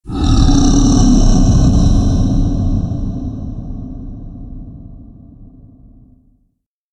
horror
Monster Roar